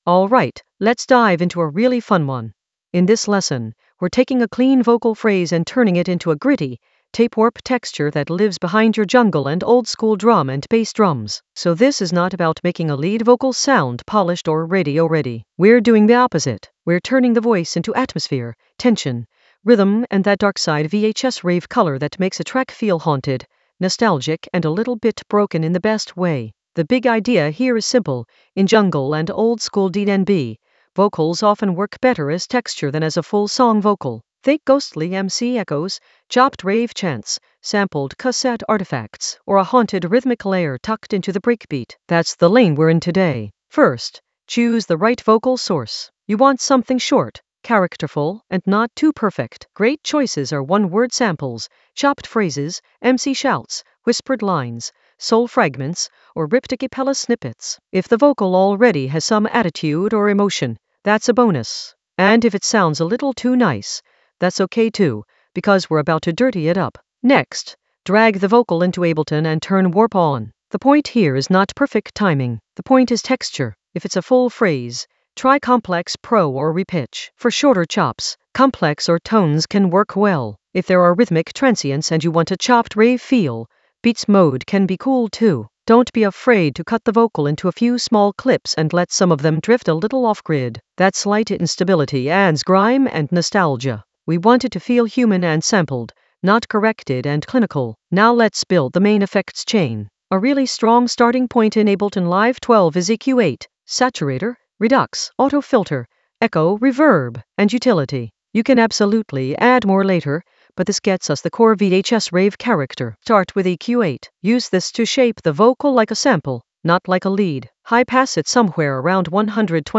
An AI-generated intermediate Ableton lesson focused on Darkside Ableton Live 12 vocal texture deep dive for VHS-rave color for jungle oldskool DnB vibes in the Drums area of drum and bass production.
Narrated lesson audio
The voice track includes the tutorial plus extra teacher commentary.